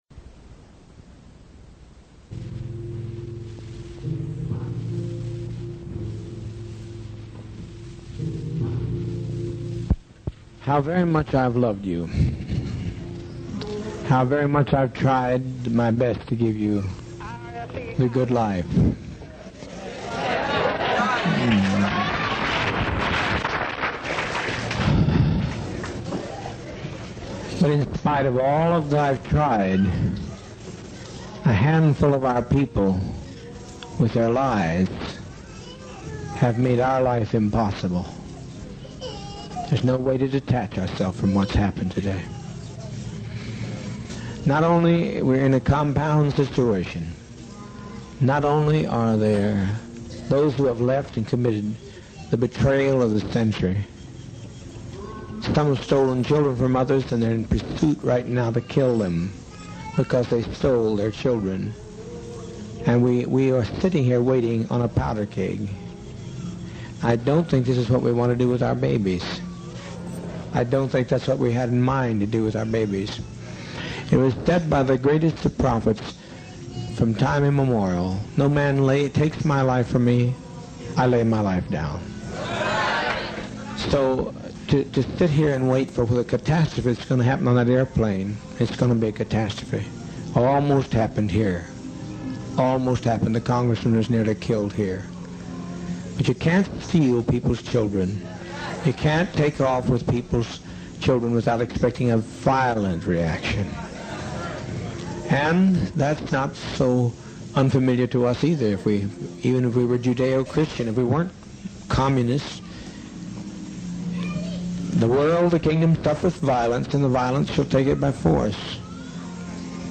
Rev. Jim Jones speaks to members of the People's Temple before the mass suicide